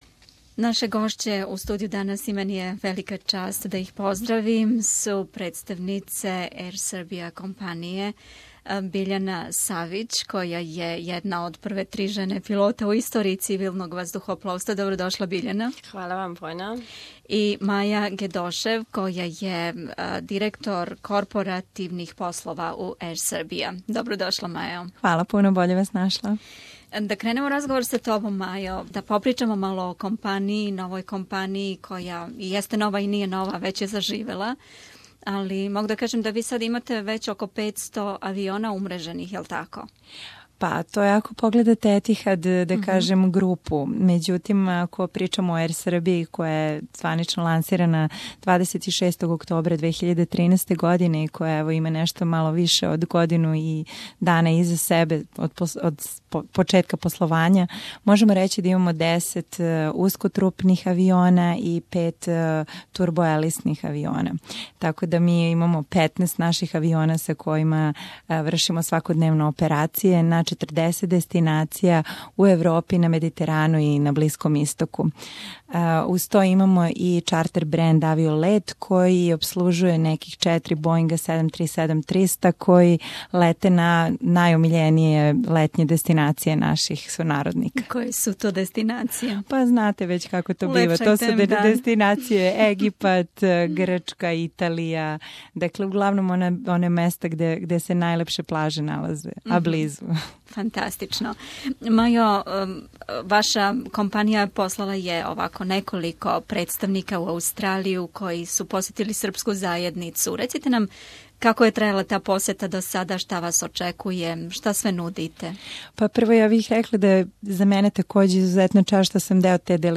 СБС радио на српском језику данас је био домаћин драгим гостима из српске ваздухопловне компаније Ер Србија.